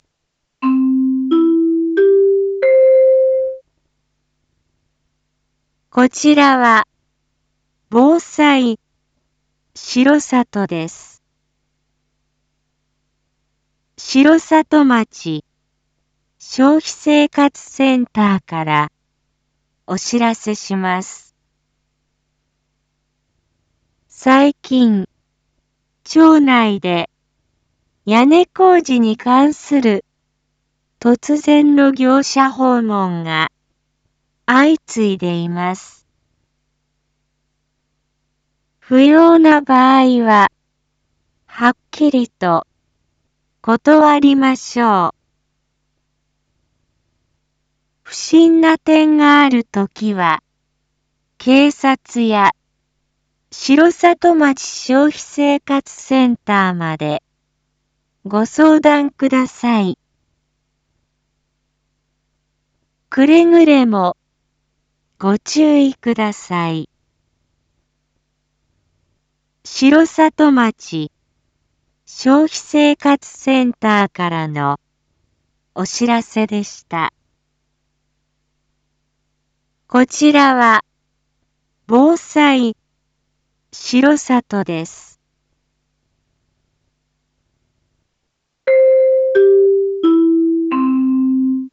Back Home 一般放送情報 音声放送 再生 一般放送情報 登録日時：2023-02-14 19:01:33 タイトル：消費生活センターからの注意喚起 インフォメーション：こちらは、防災しろさとです。